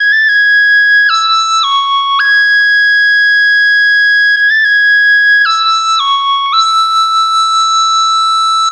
Synth Whistle 02.wav